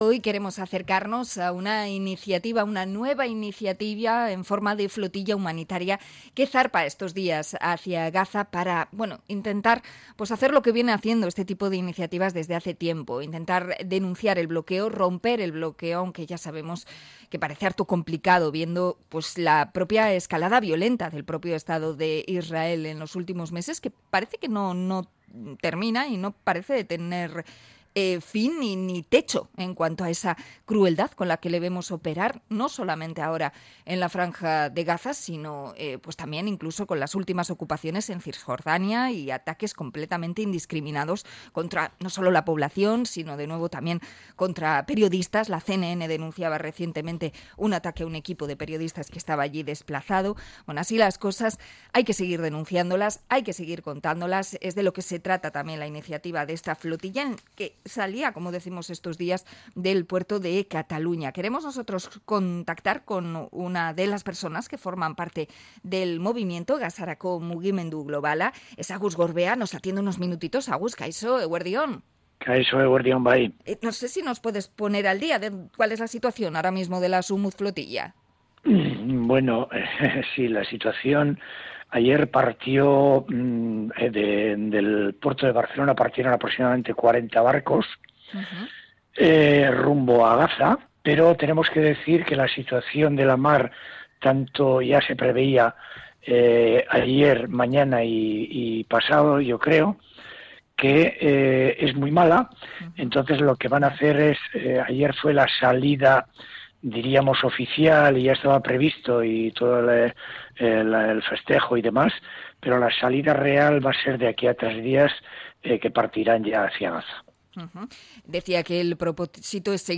Entrevista a miembro de Gazarako Mugimendu Globala por la Global Sumud Flotilla